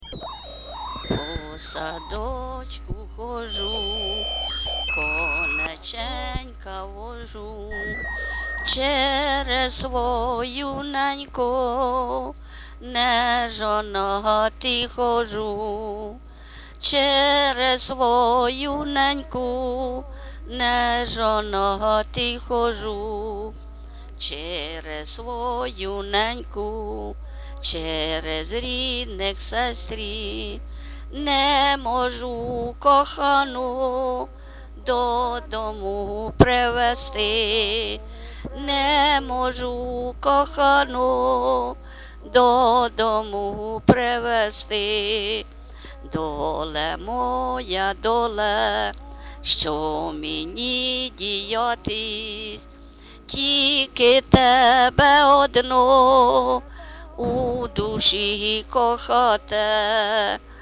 ЖанрПісні з особистого та родинного життя, Сучасні пісні та новотвори
Місце записус. Нижні Рівні, Чутівський район, Полтавська обл., Україна, Слобожанщина